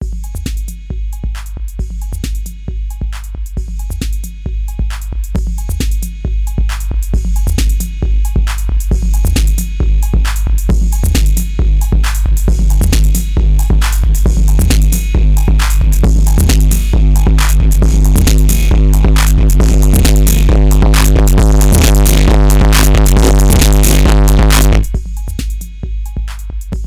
So, here’s a random clean 808 loop from Soundcloud thru a BX-4 mixer with preamp gain increased gradually from zero until maxed out. No other processing and sorry for bad looping, I was too lazy to fix it.
808bx.aif (4.5 MB)